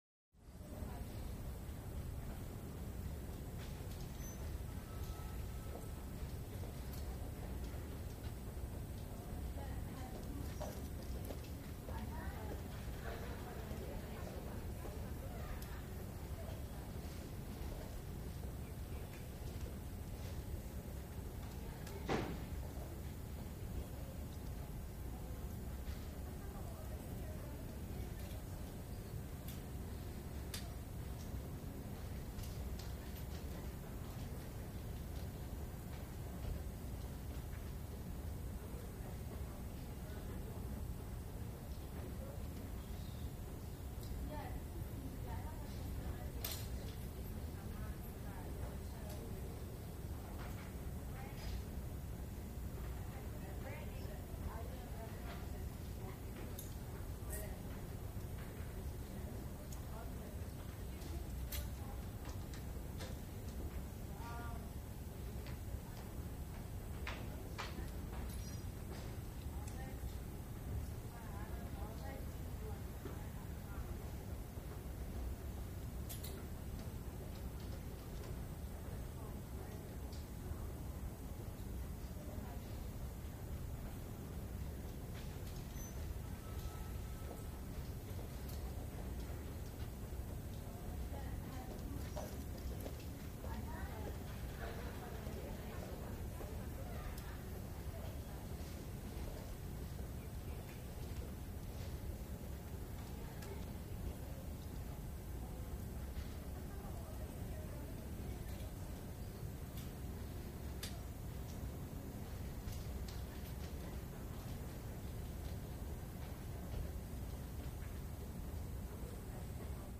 Hallway - Distant Voices & Phones / Quiet Lobby